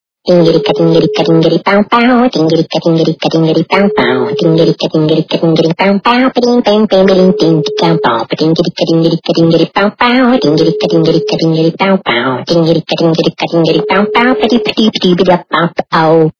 Люди фразы